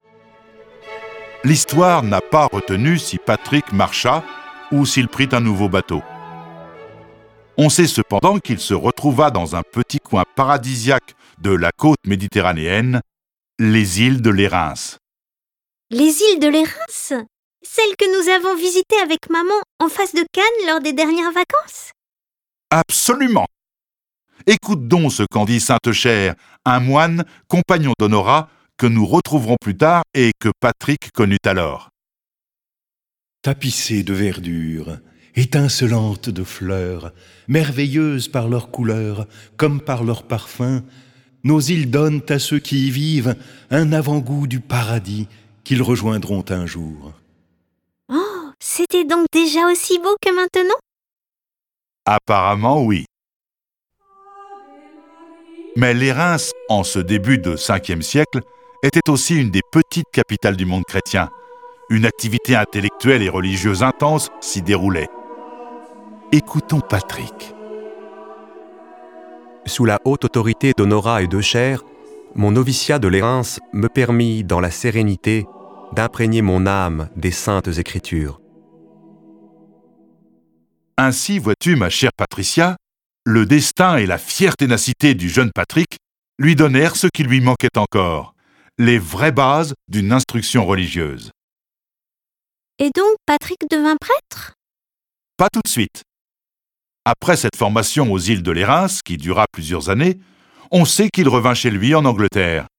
Cette version sonore de la vie de Patrick est animée par dix voix et accompagnée de près de quarante morceaux de musique classique.
Le récit et les dialogues sont illustrés avec les musiques de Bach, Bizet, Debussy, Dvorak, Grieg, Haendel, Haydn, Mendelssohn, Mozart, Pergolese, Schubert, Schumann, Tchaikovski, Telemann, Vivaldi.